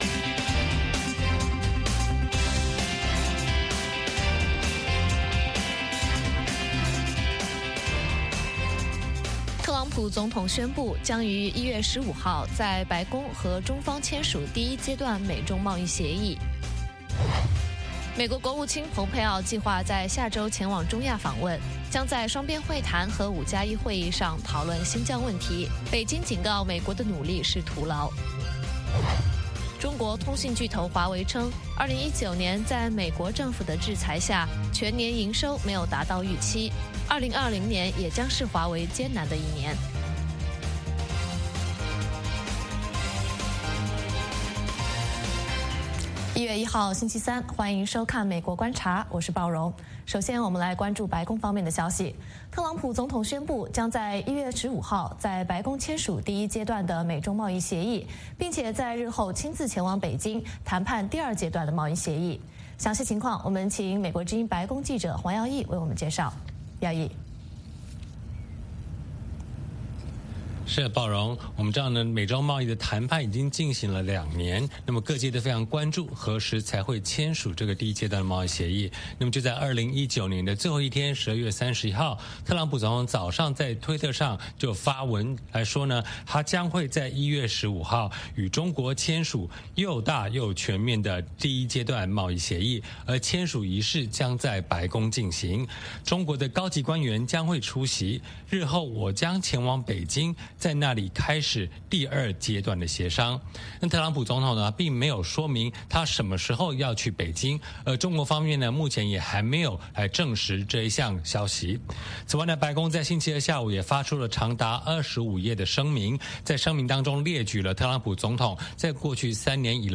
北京时间早上6-7点广播节目，电视、广播同步播出VOA卫视美国观察。 “VOA卫视 美国观察”掌握美国最重要的消息，深入解读美国选举，政治，经济，外交，人文，美中关系等全方位话题。节目邀请重量级嘉宾参与讨论。